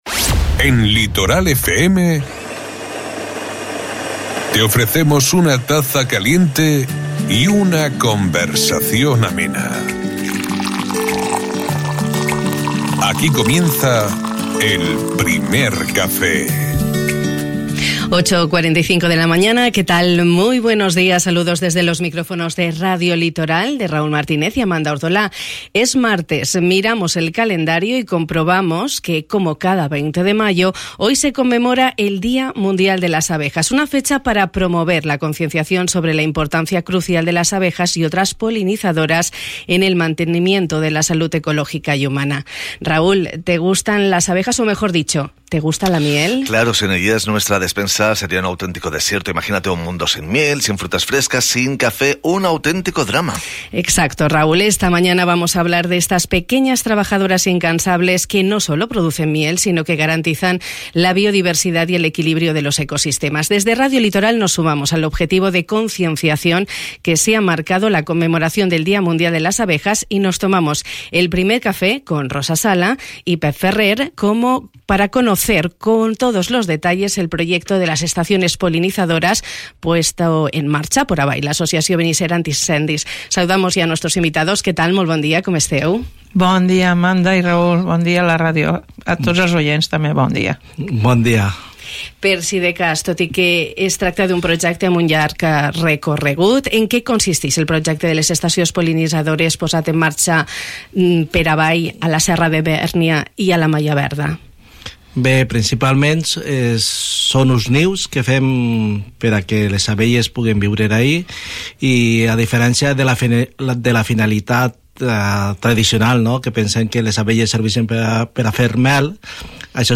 Un espai radiofònic on hem pogut conèixer el projecte d'estacions pol·linitzadores posat en marxa a la Serra de Bèrnia ia la Mallà Verda per ABAI, l'Associació Benissera Antiincendis.